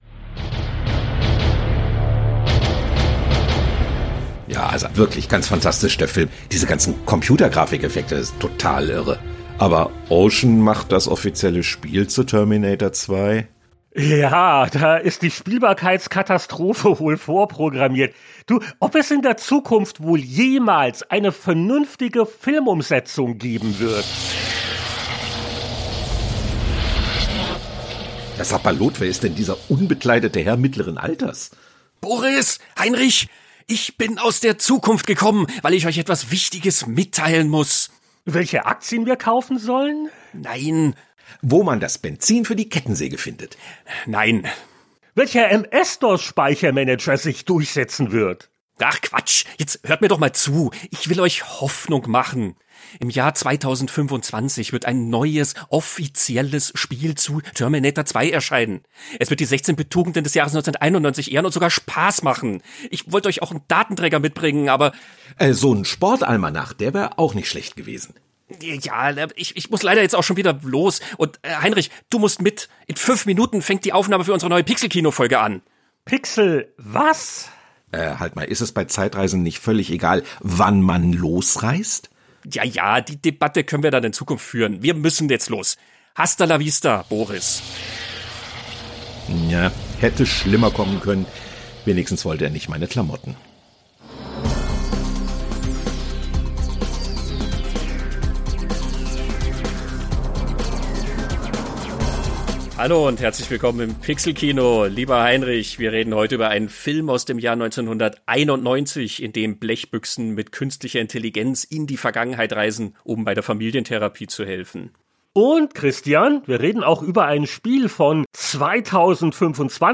Quizmaster